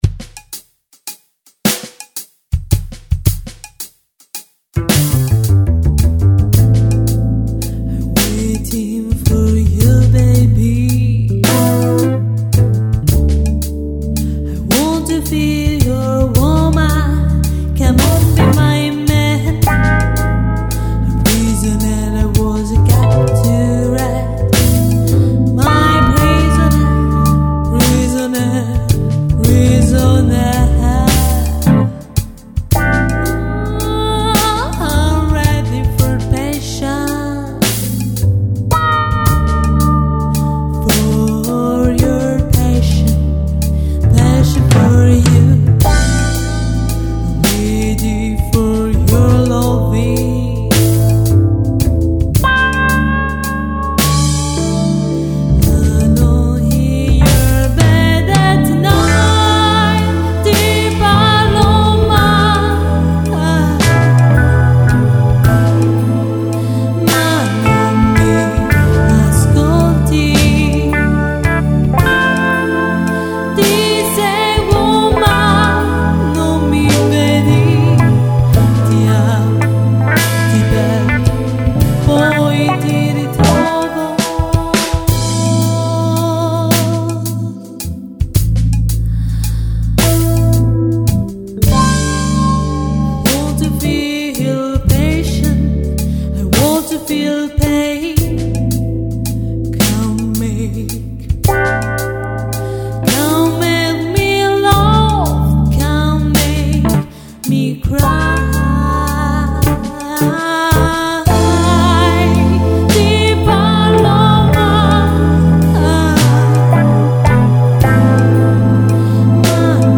GenereBlues / Soul